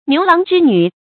成語注音 ㄋㄧㄨˊ ㄌㄤˊ ㄓㄧ ㄋㄩˇ
成語拼音 niú láng zhī nǚ
牛郎織女發音